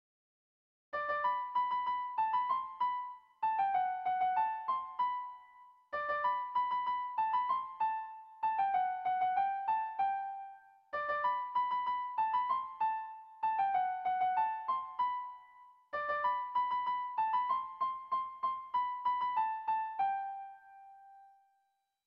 Lehendabiziko udaberria - Bertso melodies - BDB.
Sentimenduzkoa
Zortziko handia (hg) / Lau puntuko handia (ip)
A1A2A1A3